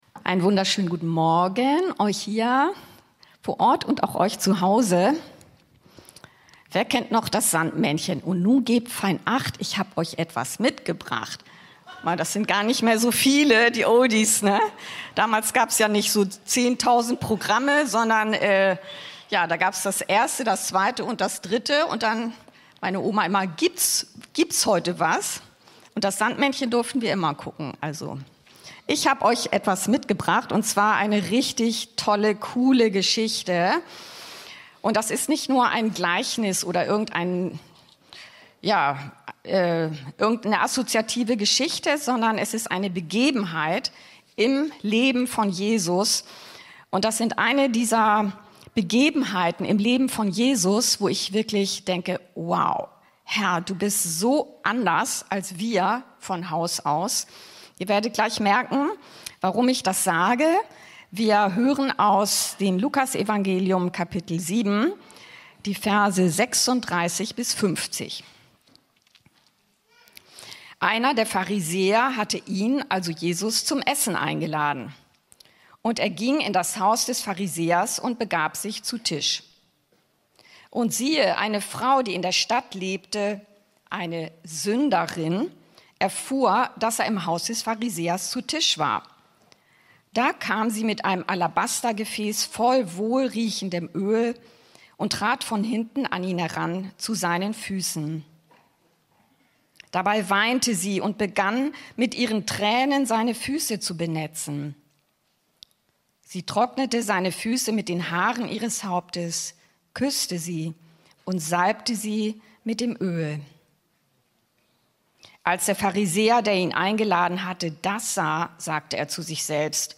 Jesus lieben mit Haut und Haaren,Luk 7,36-50 ~ Anskar-Kirche Hamburg- Predigten Podcast